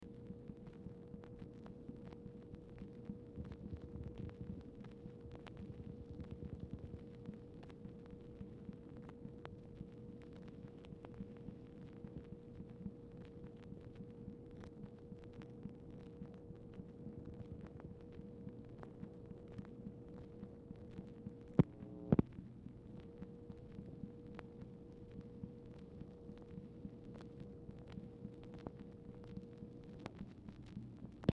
Format Dictation belt
Series White House Telephone Recordings and Transcripts Speaker 2 MACHINE NOISE Specific Item Type Telephone conversation